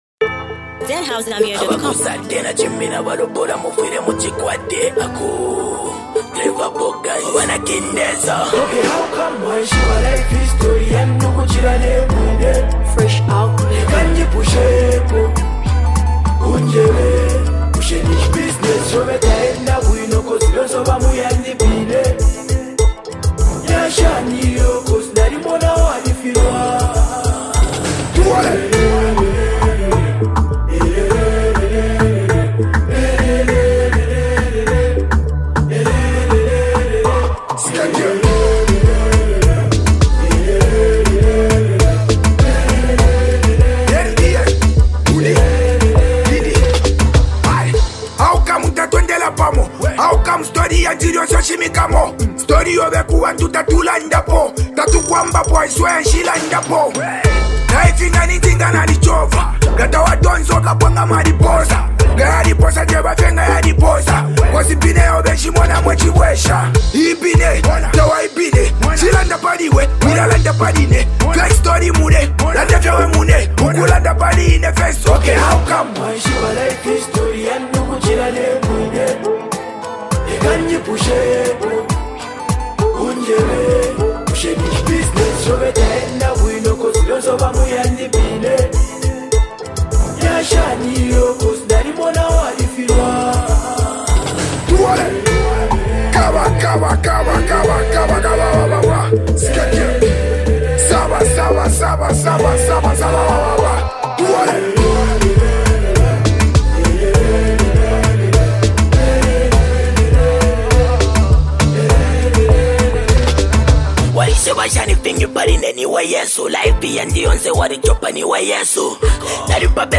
The hitmakers are back with another banger!